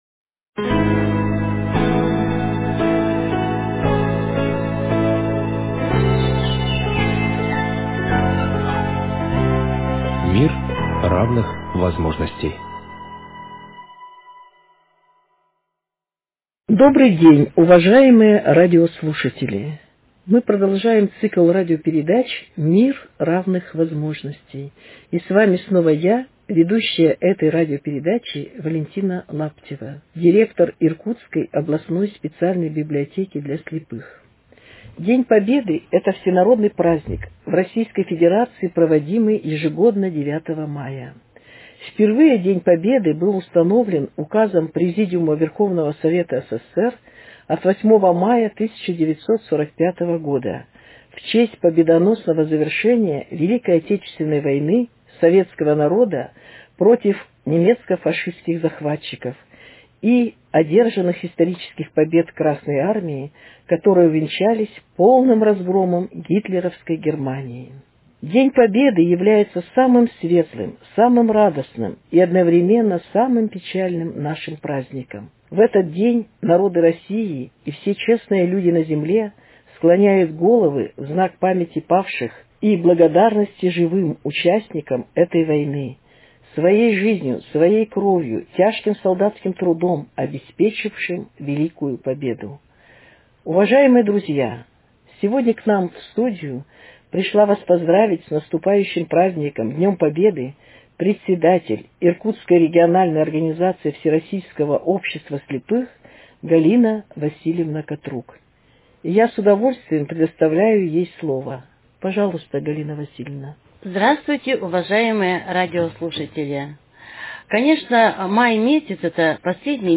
участники радио–встречи сотрудники библиотеки и артисты театров Иркутска.